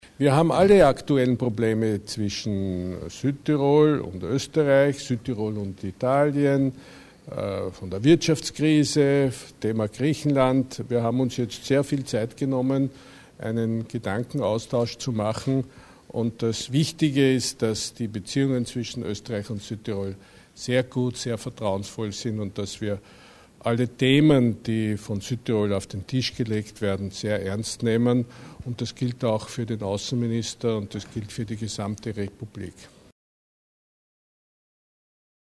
Bundespräsident Fischer zu den behandelten Themen